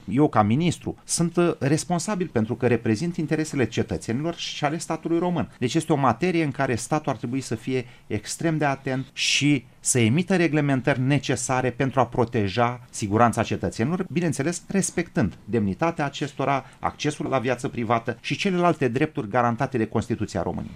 Ministrul Comunicaţiilor şi pentru Societatea Informaţională, Marius Bostan, a declarat pentru Radio România Actualităţi că legea trebuie să respecte drepturile cetăţenilor la viaţă privată: